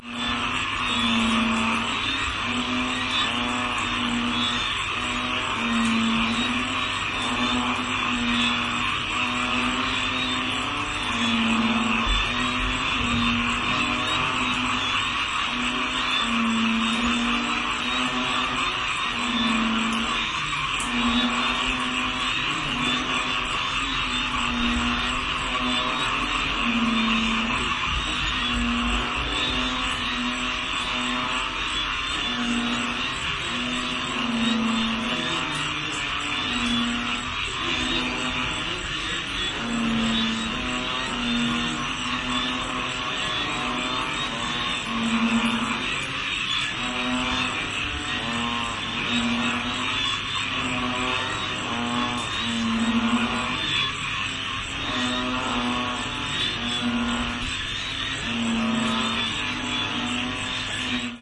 诡异的青蛙氛围 泰国
描述：从我的阳台上记录室外，在泰国清迈很晚。蟾蜍发出了很大的声音（......！）。它们不是那么大，但它们可以发出这么大的声音！像奶牛或其他东西。
Tag: 泰国清迈 分机 蟾蜍 国家 青蛙 现场记录